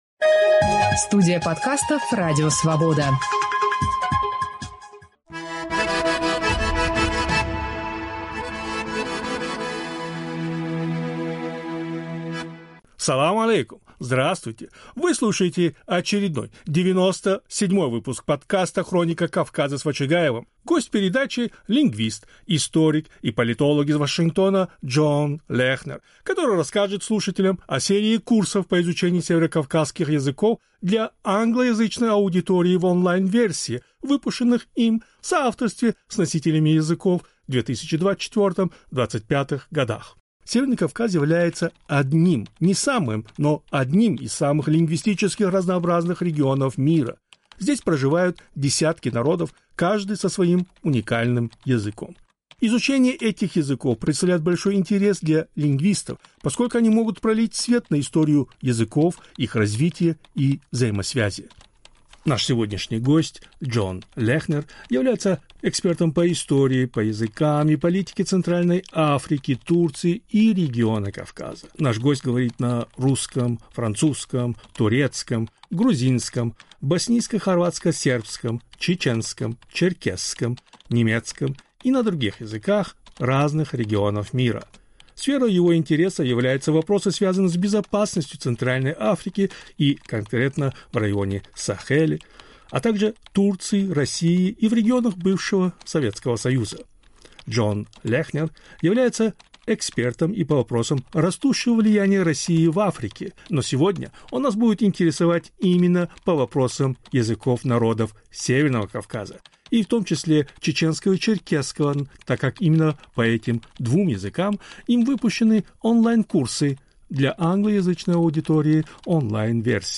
Гость передачи